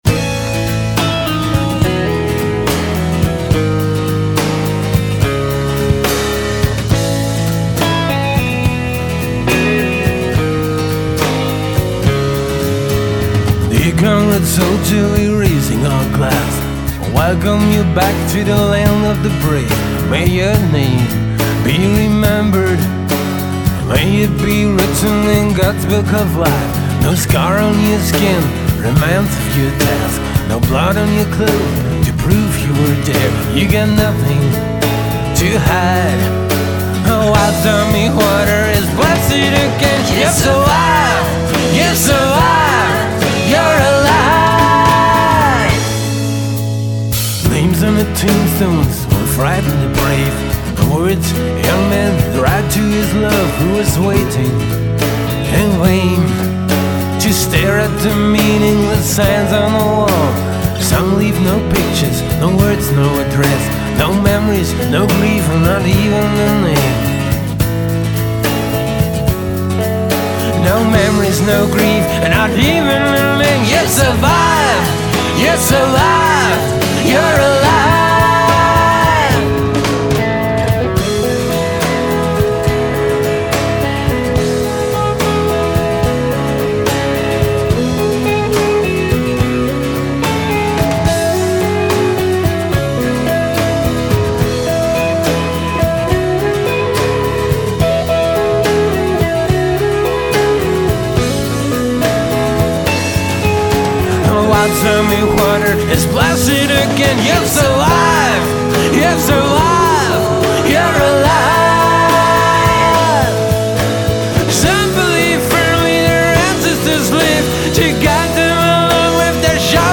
Aufnahmejahr: 2003, Aufnahmeort: Sound Vortex Nashville
drums
bass
electric guitars
backing vocals
vocals, acoustic guitar